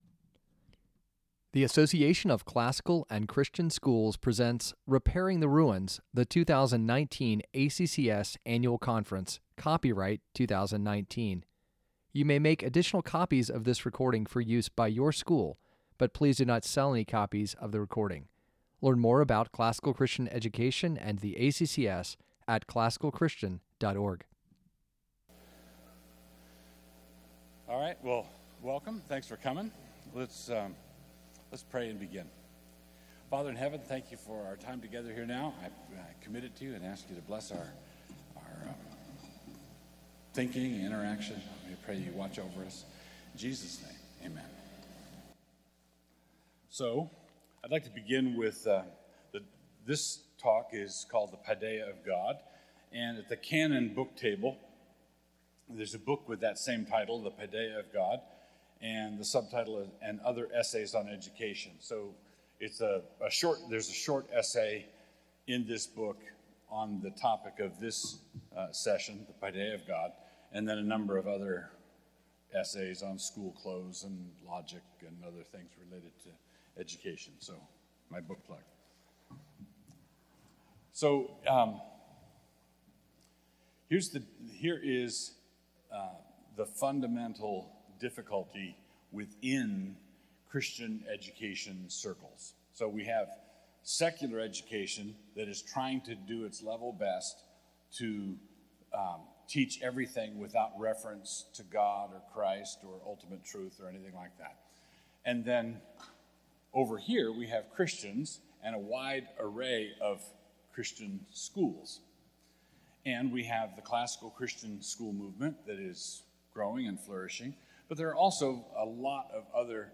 2019 Foundations Talk | 59:33 | All Grade Levels, Culture & Faith, Virtue, Character, Discipline
Jul 29, 2019 | All Grade Levels, Conference Talks, Culture & Faith, Foundations Talk, Library, Media_Audio, Virtue, Character, Discipline | 0 comments